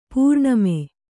♪ pūrṇame